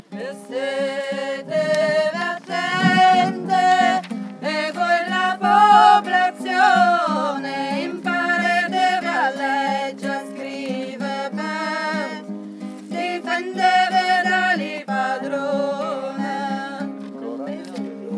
altos_refrain.mp3